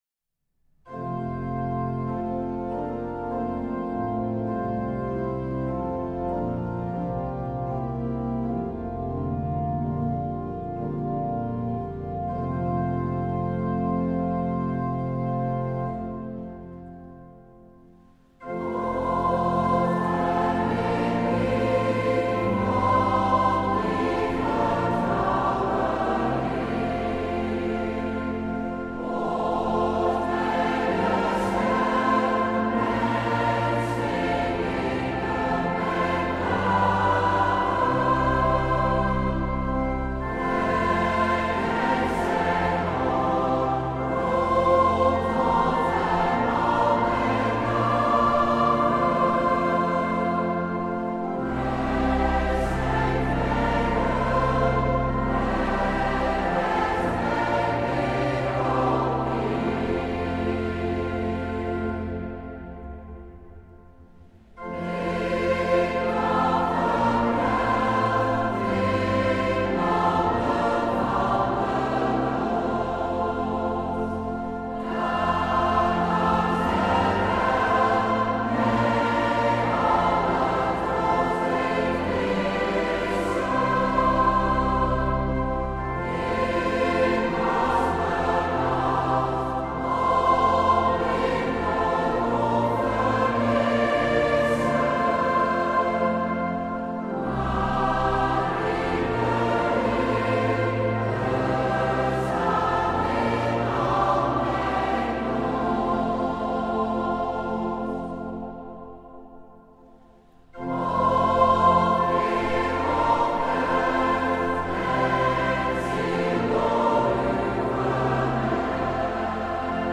Toonsoort Ges ( 6 mollen )